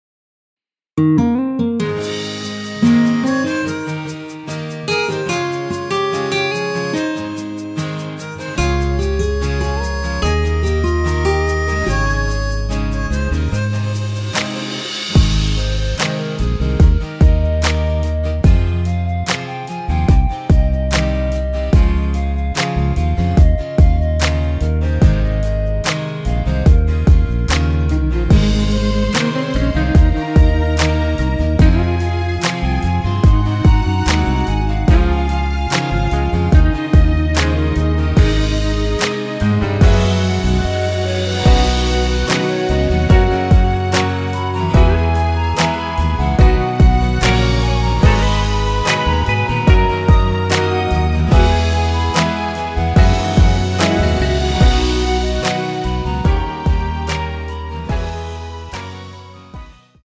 멜로디 포힘된(-1) MR 입니다.(미리듣기 참조)
Ab
앞부분30초, 뒷부분30초씩 편집해서 올려 드리고 있습니다.
곡명 옆 (-1)은 반음 내림, (+1)은 반음 올림 입니다.
(멜로디 MR)은 가이드 멜로디가 포함된 MR 입니다.